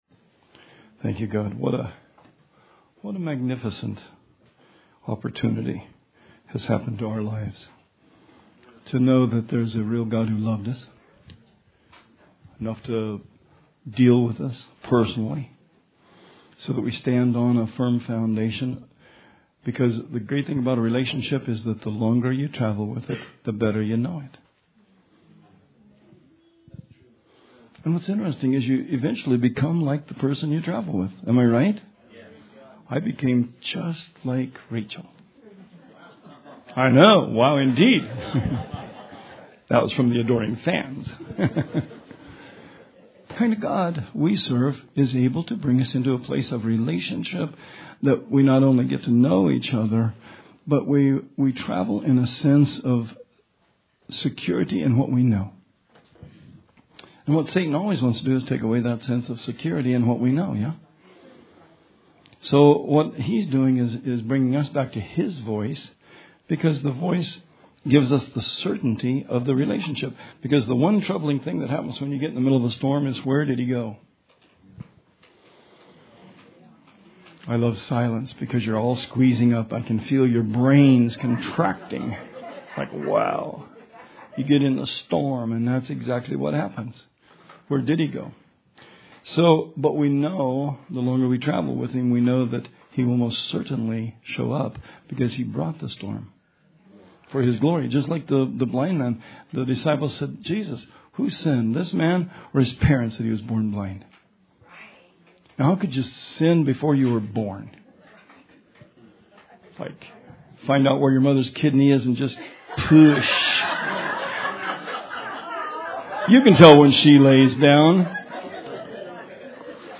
Sermon 2/3/19